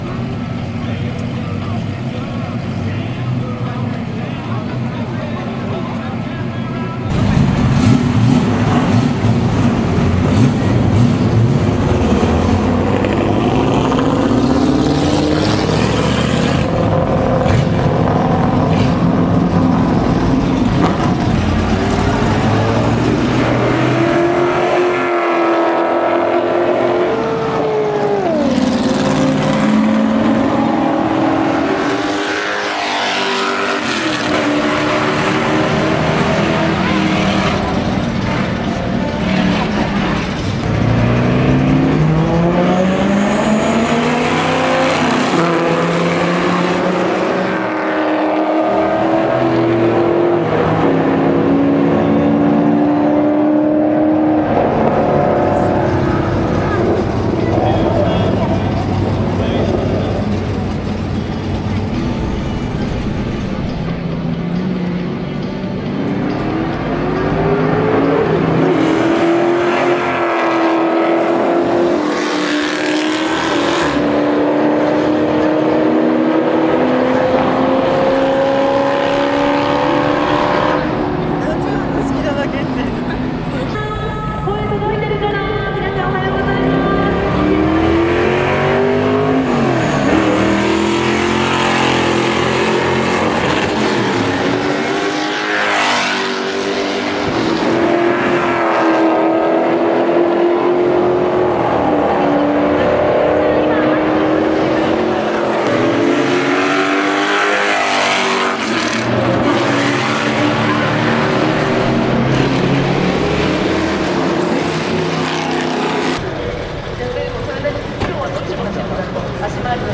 HOT ATTACK & DRAG RACE
in　ツインリンクもてぎ
ENGINE　 ：GM ZZ383EFI